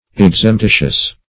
Search Result for " exemptitious" : The Collaborative International Dictionary of English v.0.48: Exemptitious \Ex`emp*ti"tious\, a. Separable.
exemptitious.mp3